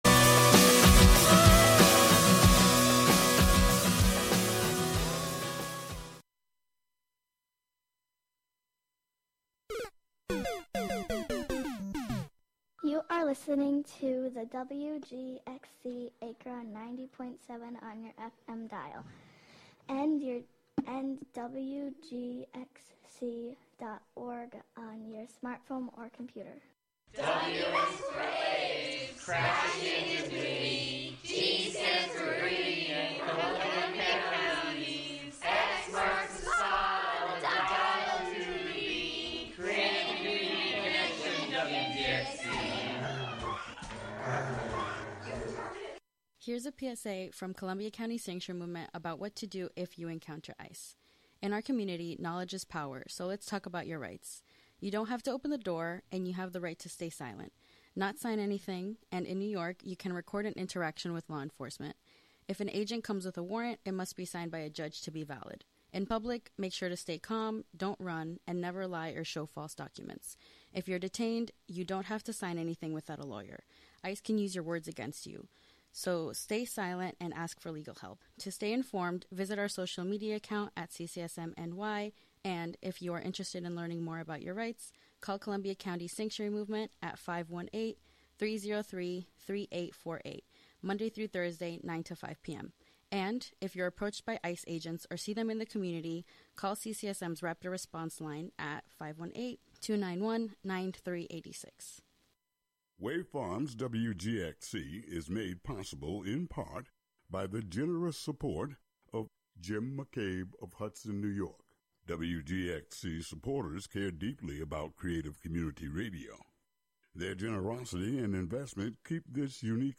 I like to start them out at 125 bpm and just keep building.